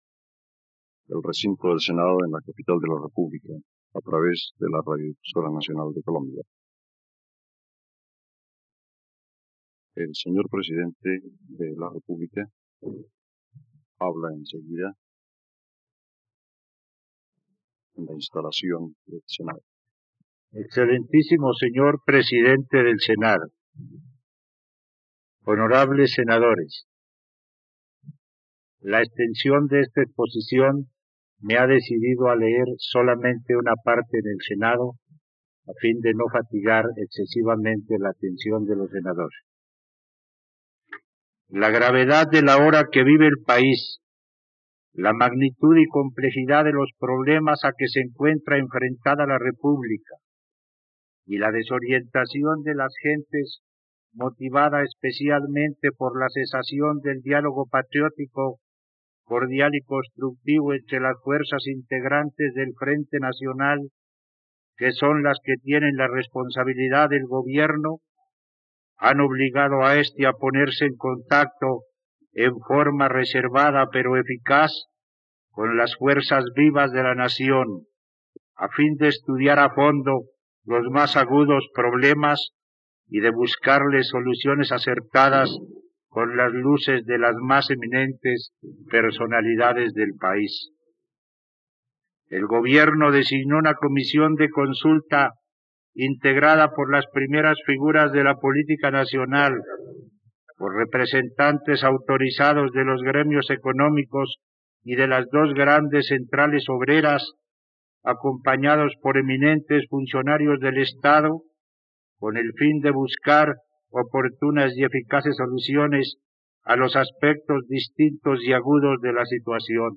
Discursos: Balance de gestión | RTVCPlay
Ante el Senado de la República: el presidente Guillermo León Valencia presenta un balance de su gobierno: aumento de la población en las ciudades, disminución de la mano de obra en el campo e incremento del desempleo y la delincuencia.